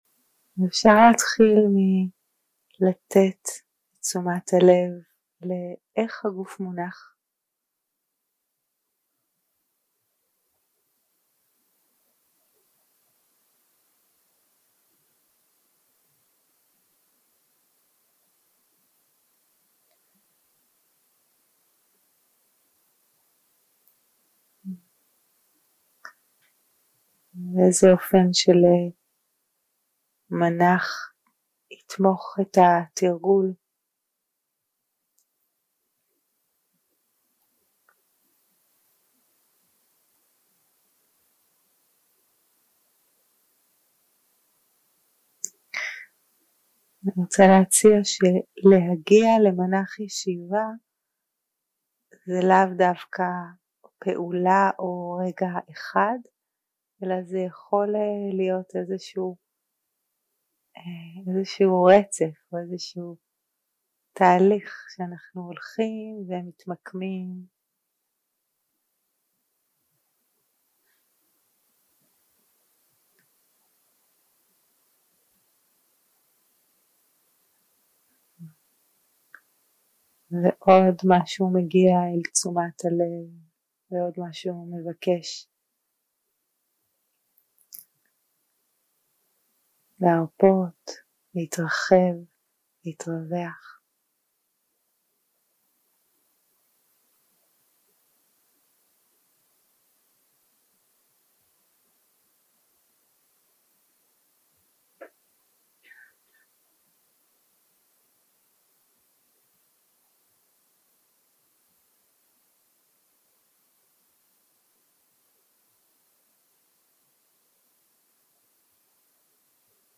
יום 4 - הקלטה 8 - ערב - מדיטציה מונחית
Dharma type: Guided meditation